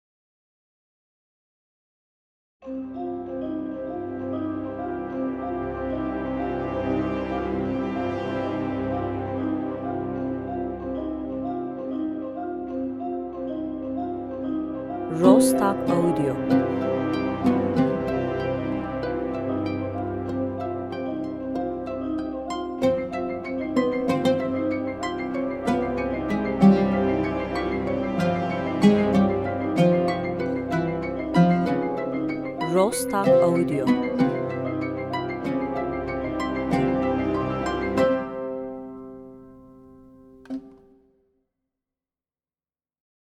enstrümantal